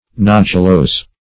Nodulose \Nod"u*lose`\ (n[o^]d"[-u]*l[=o]s`)